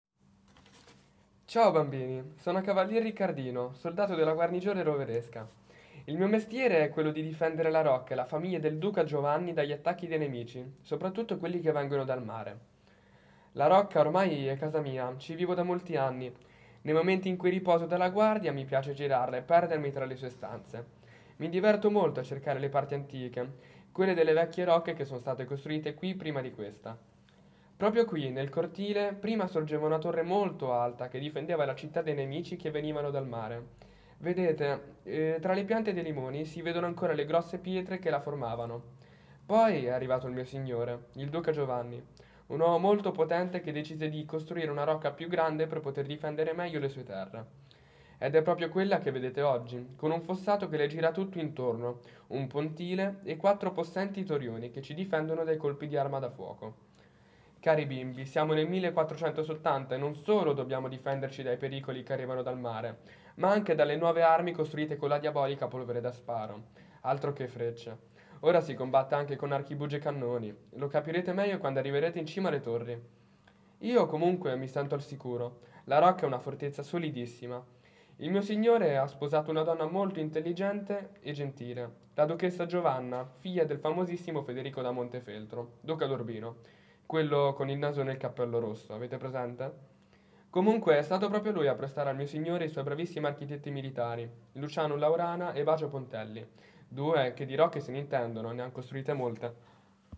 Audioguida 0-12
Ascolta Riccardino, il cavaliere della Rocca